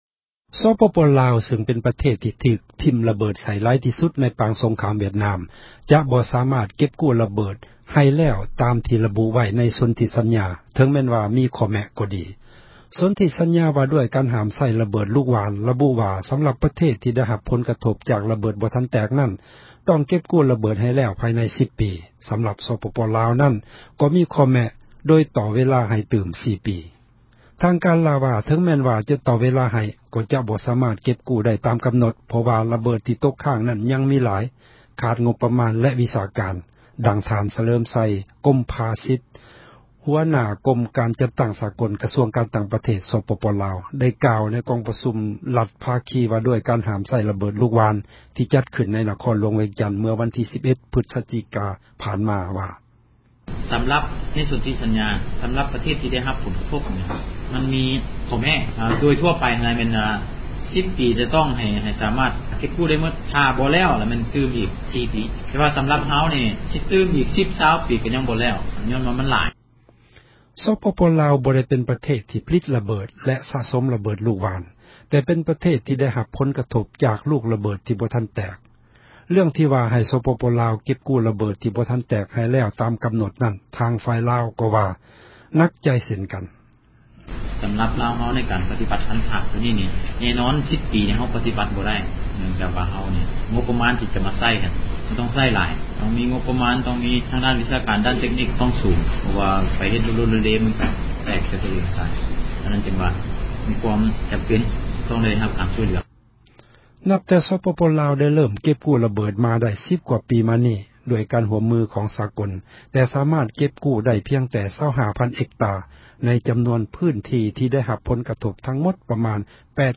ທາງການລາວ ວ່າ ເຖິງແມ່ນຈະ ຕໍ່ເວລາ ໃຫ້ກໍຈະບໍ່ ສາມາດເກັບກູ້ ໄດ້ຕາມ ກໍານົດ ເພາະວ່າ ລະເບີດ ທີ່ຕົກຄ້າງນັ້ນ ຍັງມີຫລາຍ ຂາດງົບປະມານ ແລະ ວິຊາການ, ດັ່ງທ່ານ ສະເຫລີມໃຊ ກົມມາສິດ ຫົວໜ້າກົມ ການຈັດຕັ້ງ ສາກົນ ກະຊວງການ ຕ່າງປະເທດ ສປປ ລາວ ໄດ້ກ່າວ ໃນກອງປະຊຸມ ຣັຖພາຄີ ວ່າດ້ວຍການ ຫ້າມໃຊ້ລະເບີດ ລູກຫ່ວານ ທີ່ຈັດຂື້ນ ໃນນະຄອນ ຫລວງວຽງຈັນ ເມື່ອວັນທີ່ 11 ພຶສຈິກາ ຜ່ານມາ: